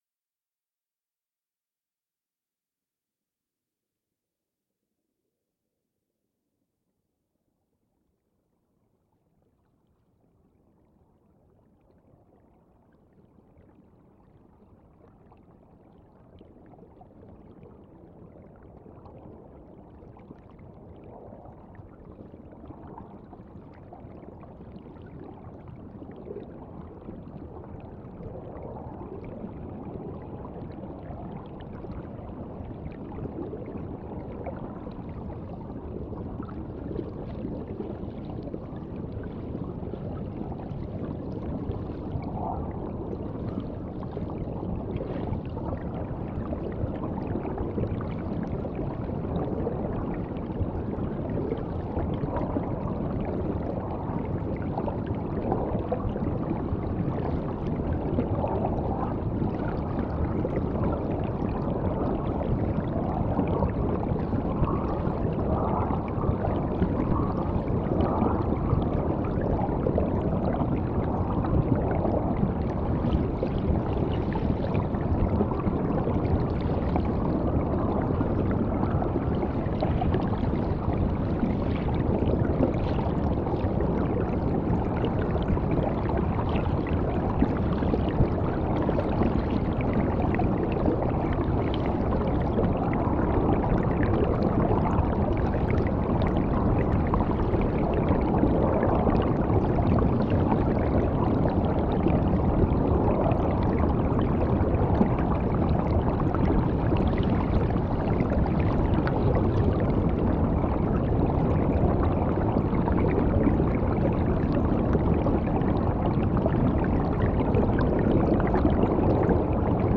Im Vordergrund ihrer künstlerischen Praxis steht die unbearbeitete Klang- und Bildaufnahme einer bestimmten Situation.
Field Recording Series by Gruenrekorder
“vorderrheinquelle (region)” begins things on a spirited note with a loose liquid bubbling. Eventually this sound becomes all-consuming until it leaves sub-aquatic spaces for the latter half.